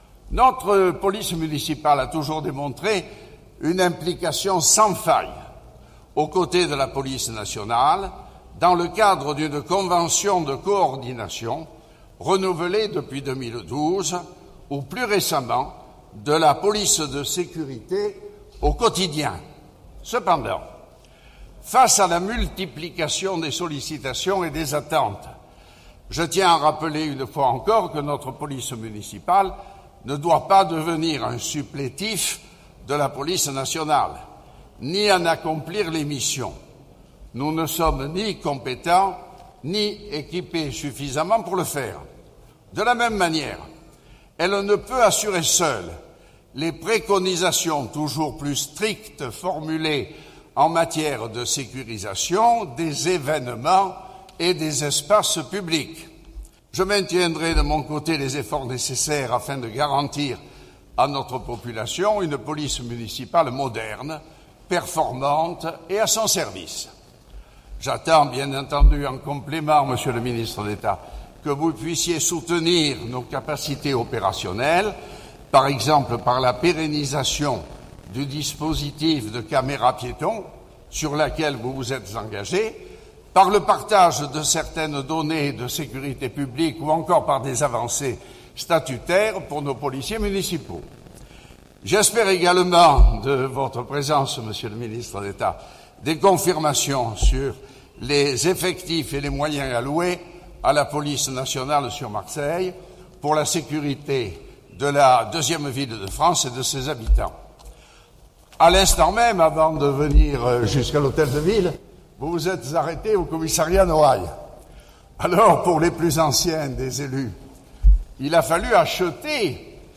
collomb_castellane_devant_presse_monte_deux.mp3